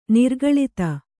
♪ nirgaḷita